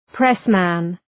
Προφορά
{‘presmən}